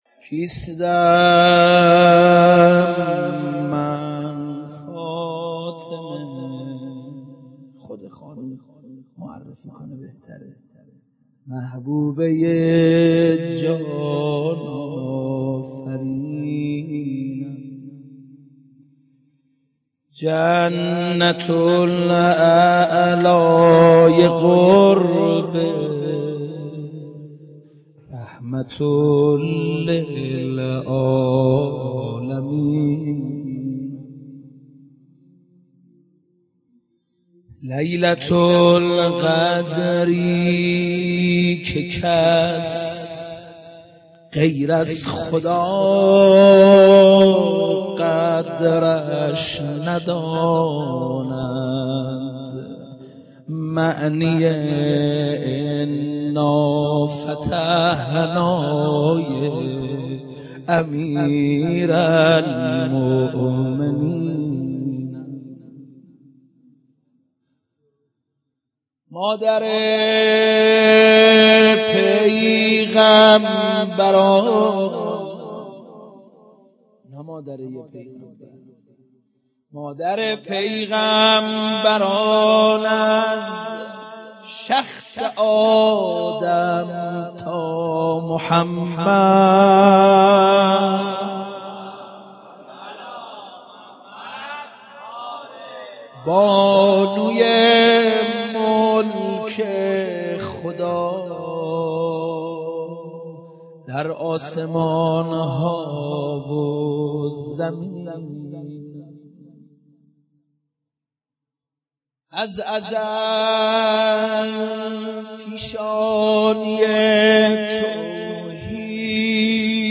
دانلود مداحی کوثر علی - دانلود ریمیکس و آهنگ جدید